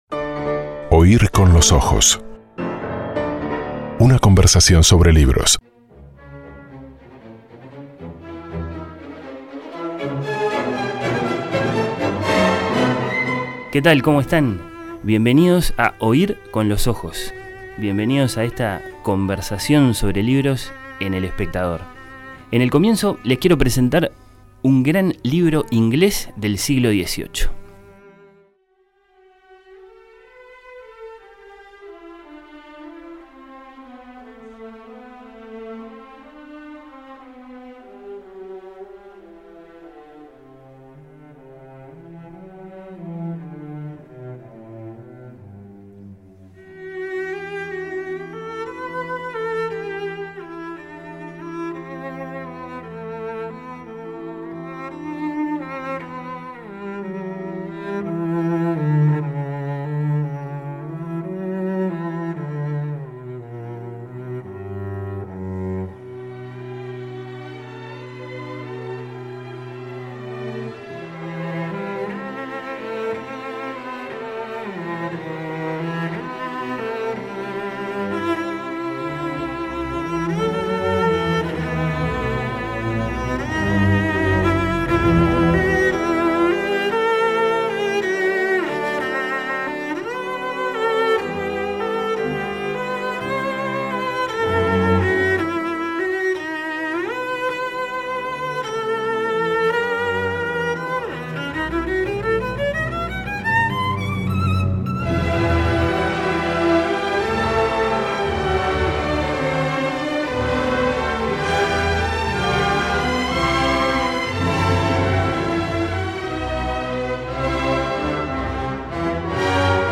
La conversación fue con el escritor uruguayo Rafael Courtoisie.